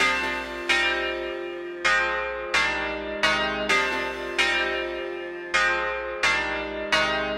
描述：向上漂浮的运动
Tag: 120 bpm Ambient Loops Fx Loops 3.46 MB wav Key : Unknown